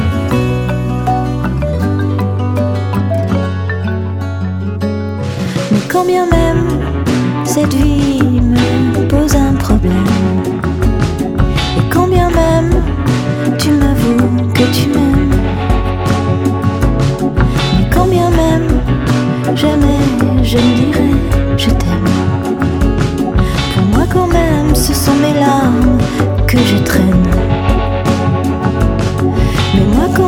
Chanson francophone - Chanson de variétés